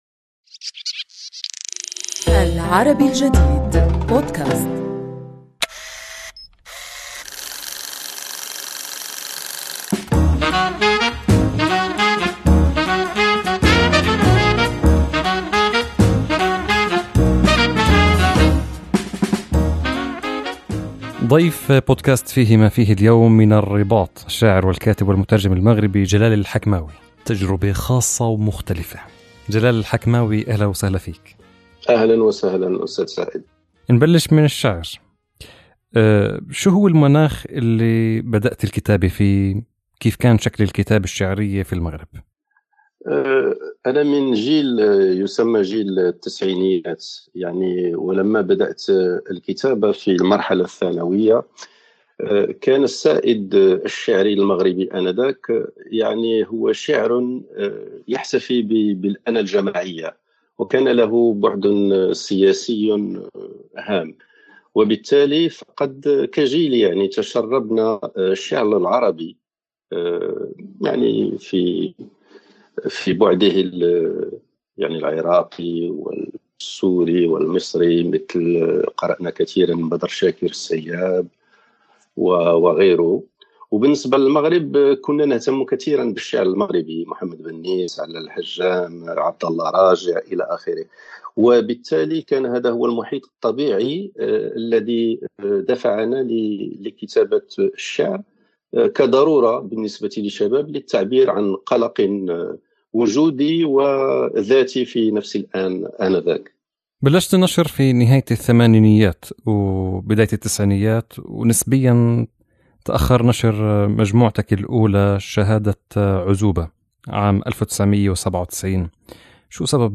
نحاوره عن الشّعر والترجمة، ومعرض الكتاب الدولي لمدينة وجدة المغربية بعد توقفه بسبب "كورونا"، وأكثر من ذلك.